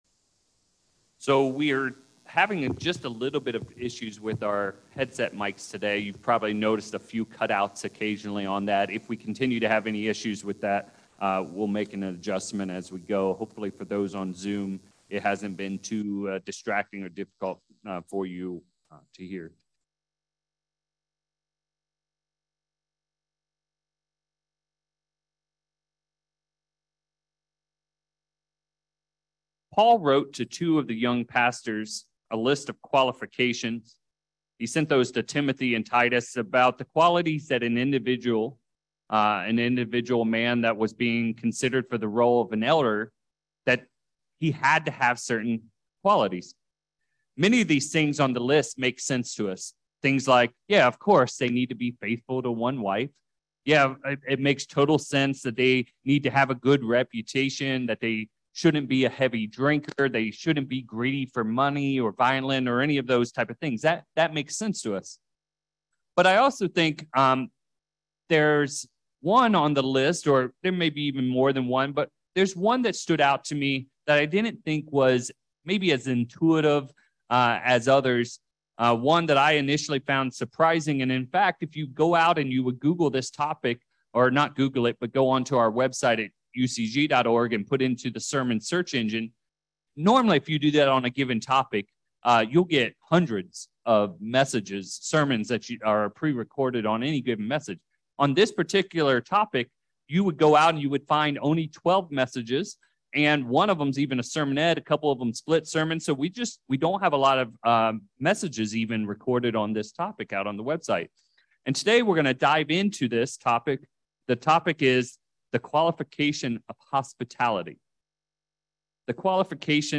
Why was hospitality so essential, that without it, an individual was unfit for that position? In this sermon, we will dive deep into the Greek words forming hospitality and related terms to understand why every elder had to be hospitable, but also why it’s a quality that the Bible shows every Christian should have.